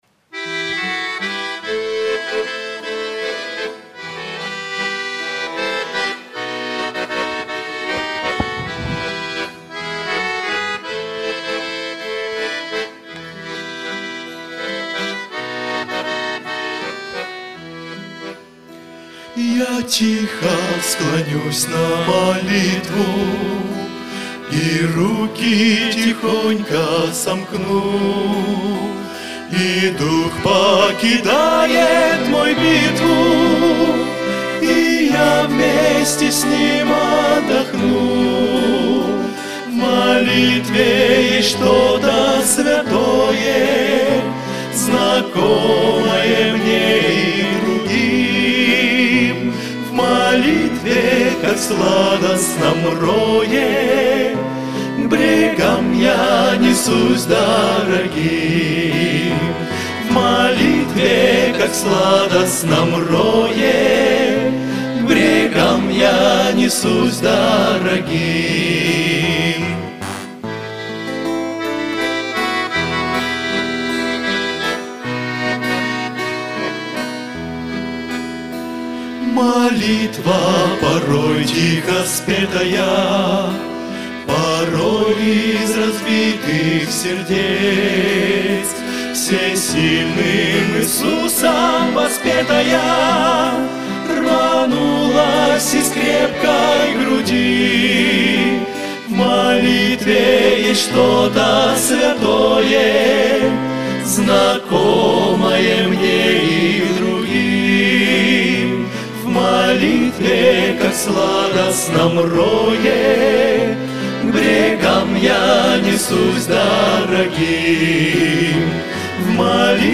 Богослужение 27.10.2024
Я тихо склонюсь на молитву - Братья (Пение)[